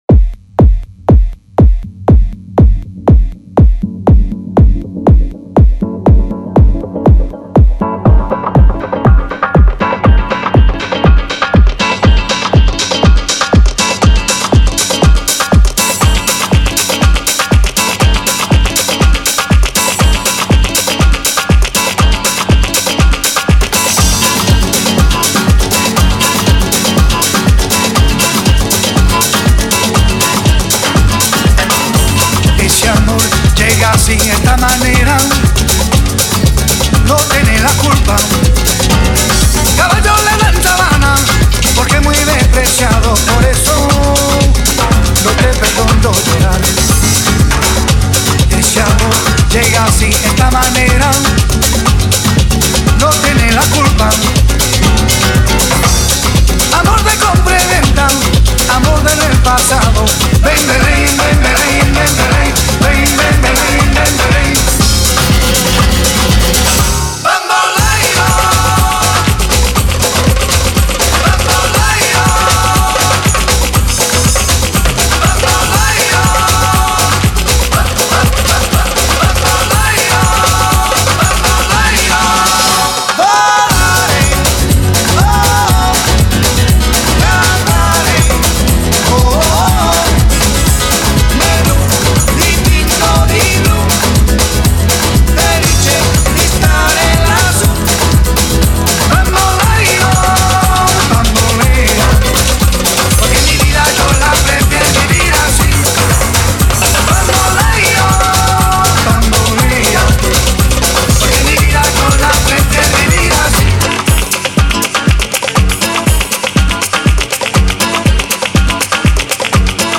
Experience the Passion of Gipsy Rhythms with a Miami Beat